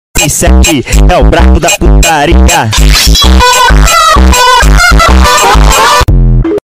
Funk gemidos Meme Effect sound effects free download